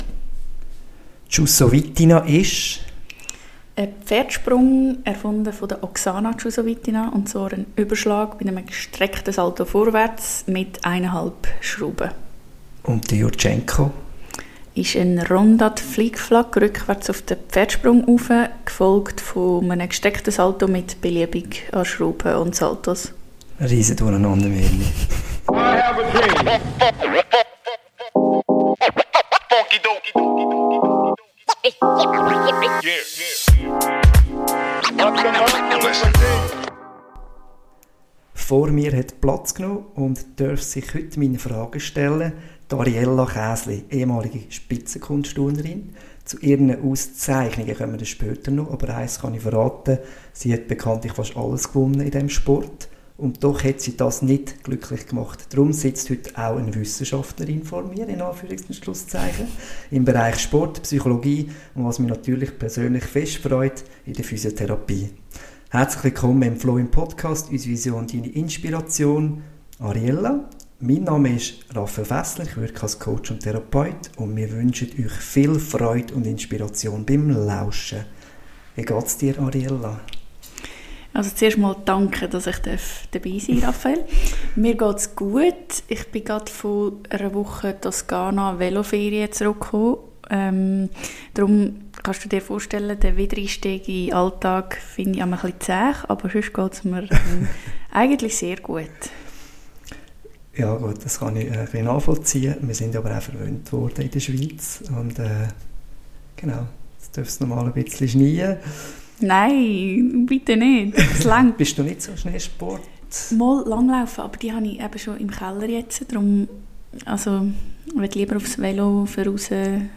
Beschreibung vor 1 Jahr Europameisterin, Vizeweltmeisterin, 20-fache Schweizermeisterin, Schweizer Sportlerin des Jahres,.. wo Gold glänzt, gibt es immer auch Staub oder Schatten.. wie dieser sich zeigte, was Ariella daraus lernte und wie sie heute Menschen inspiriert, erzählt sie uns in einem spannenden und mitreissenden Gespräch.